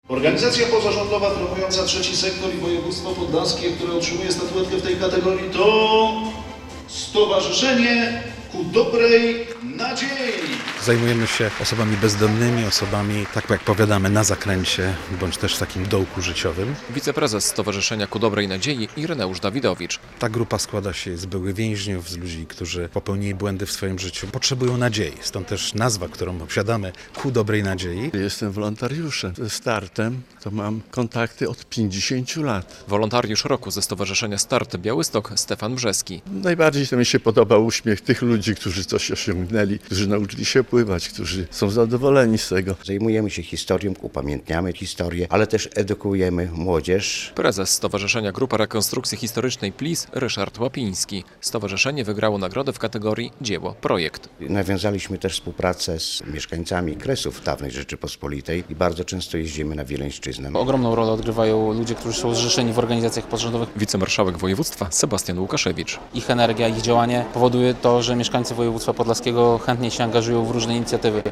Nagrody dla wolontariuszy i organizacji pozarządowych - relacja